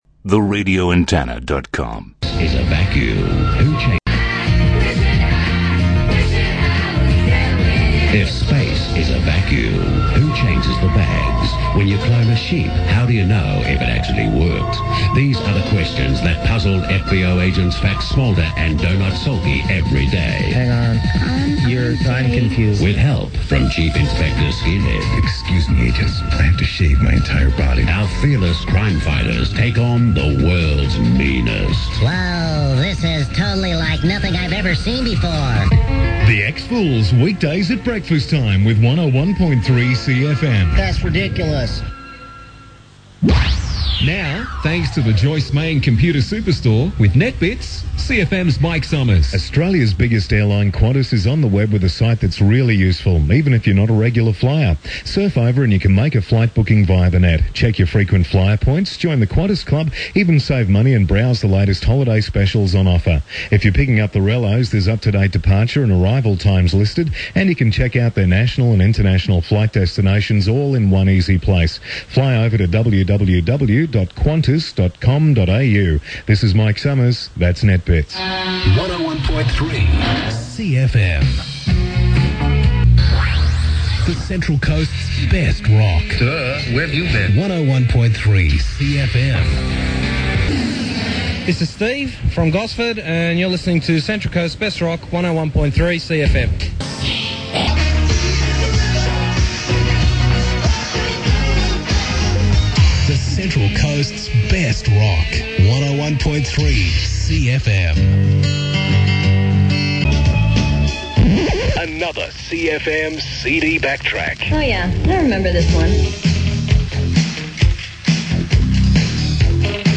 101.3SEA FM Sweepers1990
An old SONY Tape revealed this